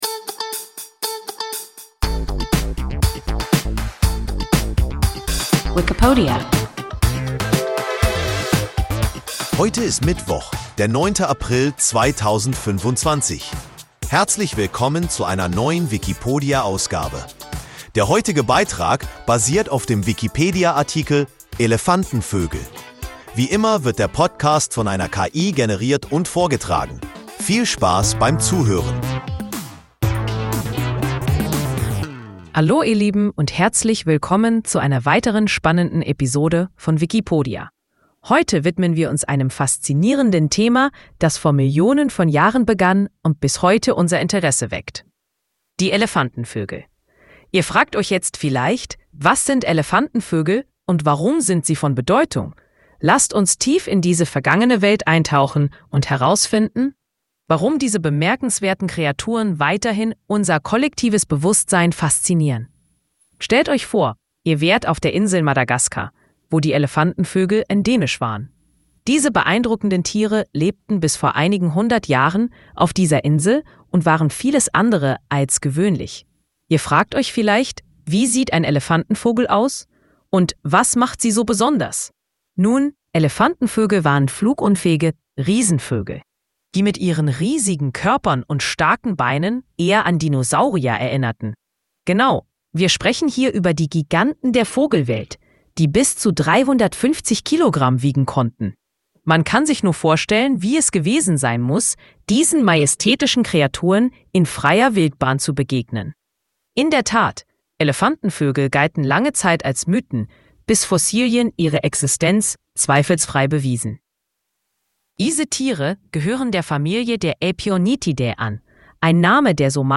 Elefantenvögel – WIKIPODIA – ein KI Podcast